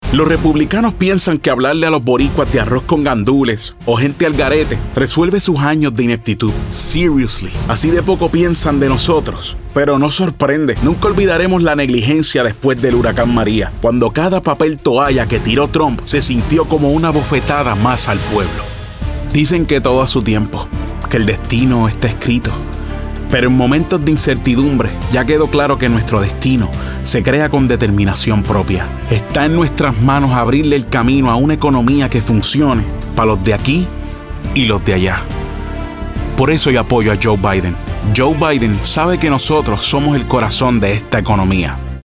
A friendly Spanish voice, great for any project!
accented, announcer, compelling, concerned, confident, foreign-language, genuine, Gravitas, informative, inspirational, political, professional, spanish-dialect, spanish-showcase, thoughtful, upbeat